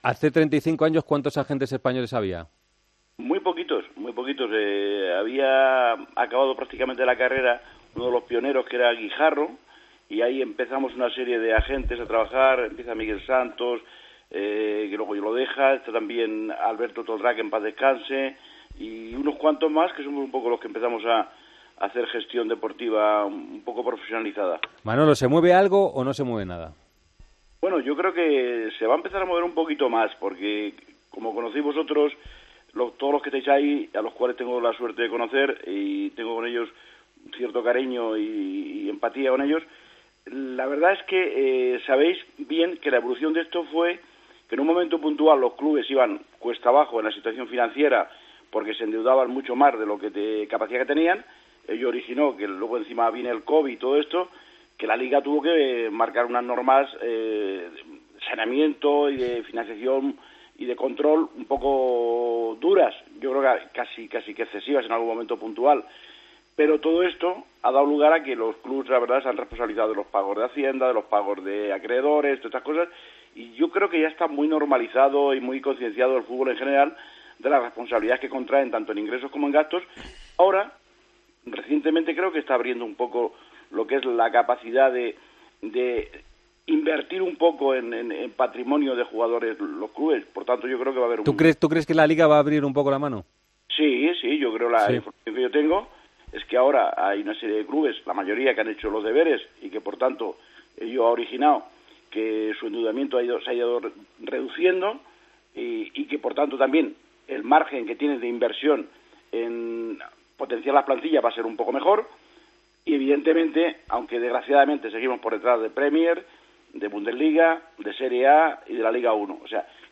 El agente FIFA ha pasado por los micrófonos de El Partidazo de COPE y ha tratado las cuestiones más importnates sobre el mercado de Primera durante las últimas temporadas.